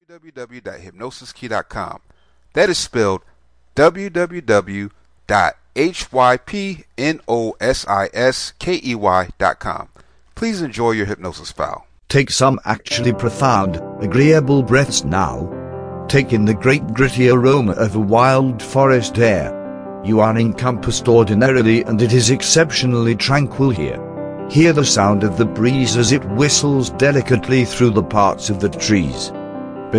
Oak Tree Self Hypnosis mp3 This is a useful induction for a